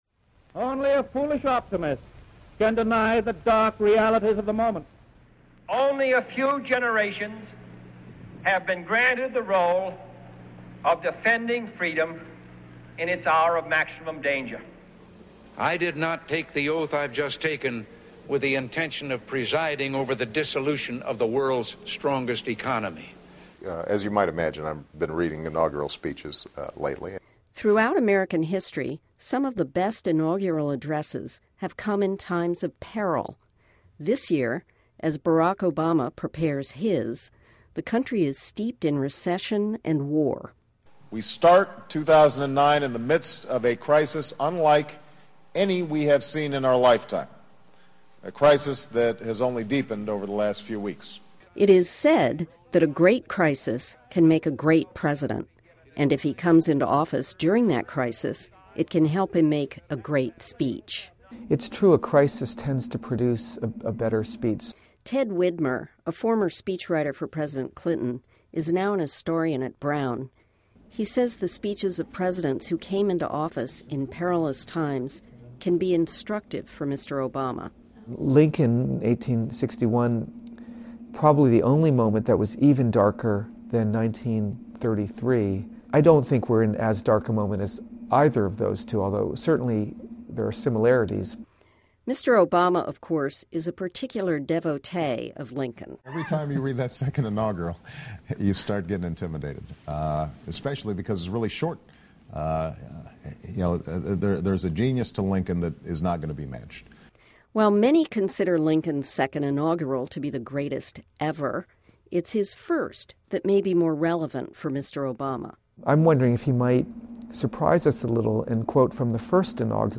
This audio was extracted from a video at the New York Times website.